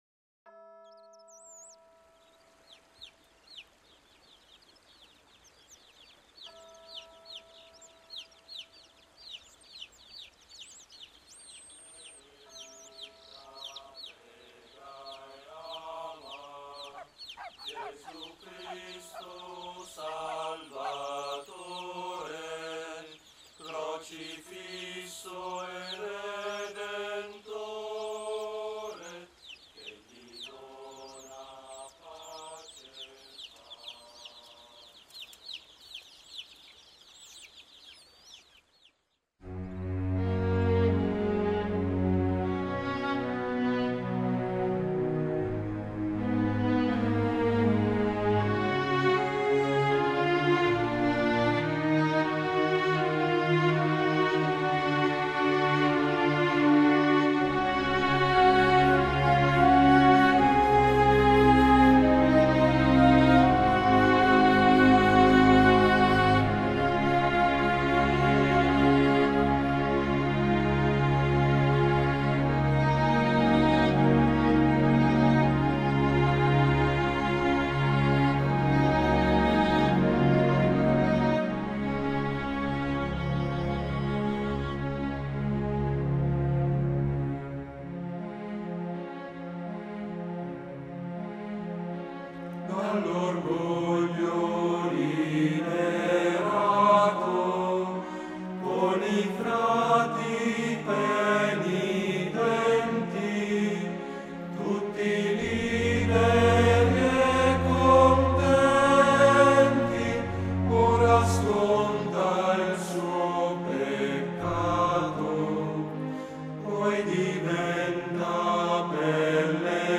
inno a s. corrado.wma